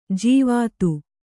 ♪ jīvātu